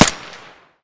Gunshot_silenced.ogg